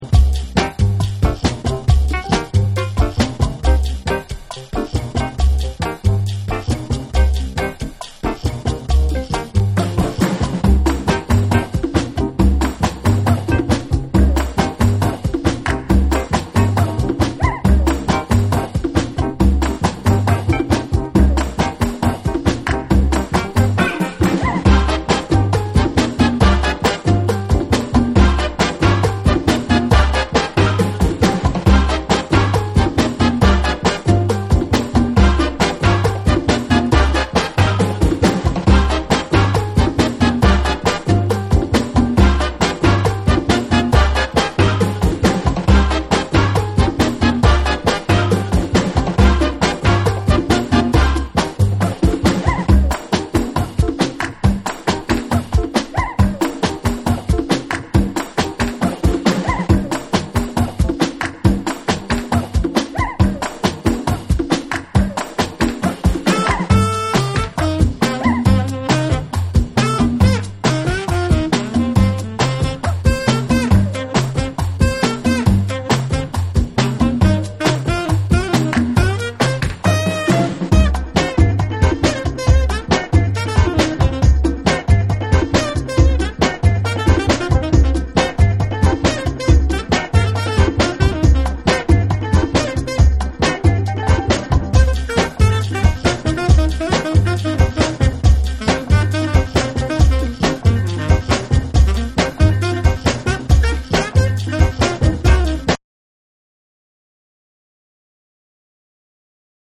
民族打楽器を使ったトライバルなブレイクビーツ上をシタールやエスニックなヴォイスサンプルが展開した5（SAMPLE2）。
プチノイズ入る箇所あり。
BREAKBEATS / ORGANIC GROOVE